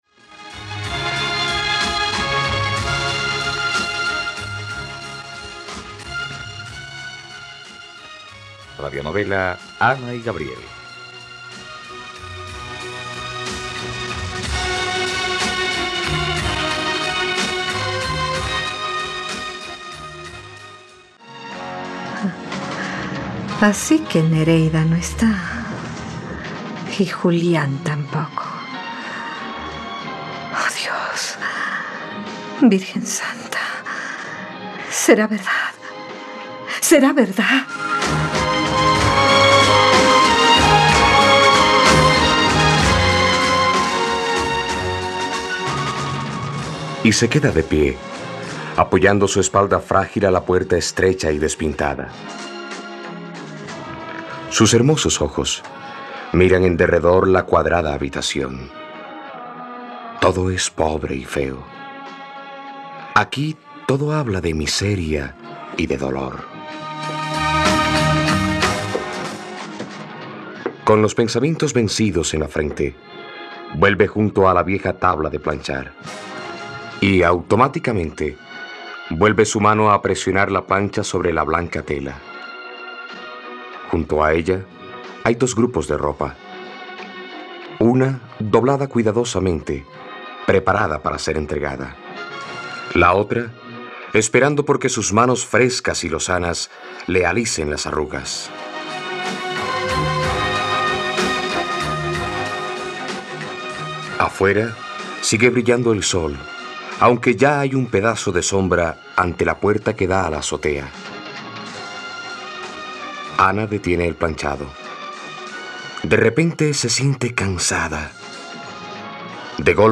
..Radionovela. Escucha ahora el segundo capítulo de la historia de amor de Ana y Gabriel en la plataforma de streaming de los colombianos: RTVCPlay.